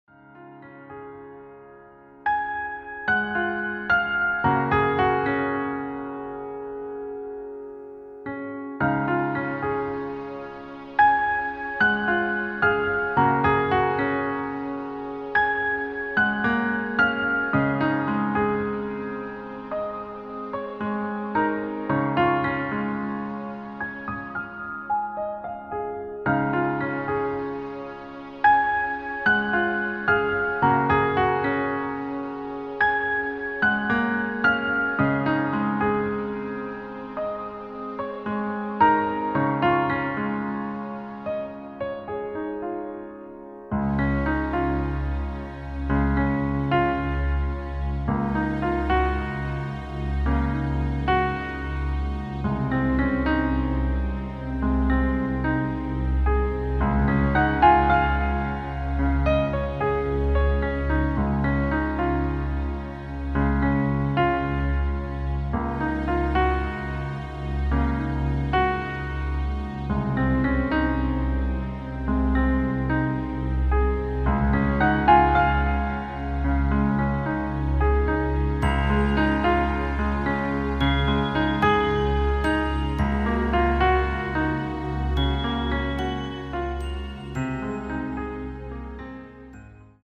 Klavierversion
• Das Instrumental beinhaltet NICHT die Leadstimme
Klavier / Streicher